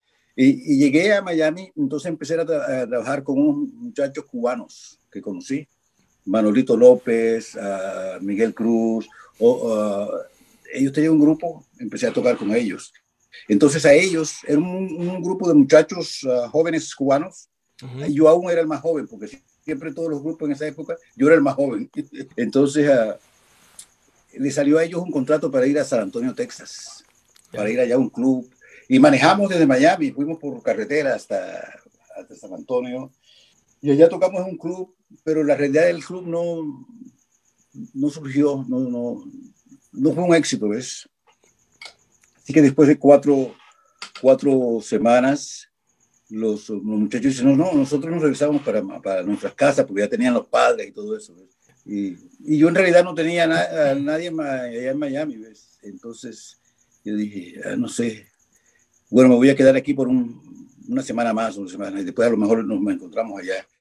Biografía Justo Almario: Audio 21. Testimonio de Almario sobre su ida a Miami y Texas
Audio 21. Testimonio de Almario sobre su ida a Miami y Texas.mp3